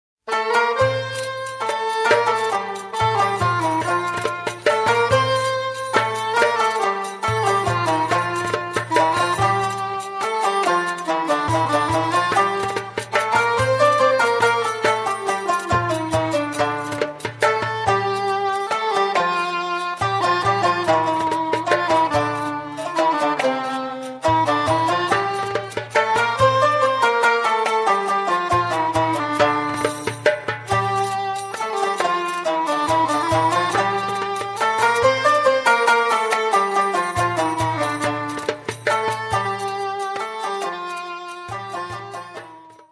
Iraqi Jewish and Arabic Song.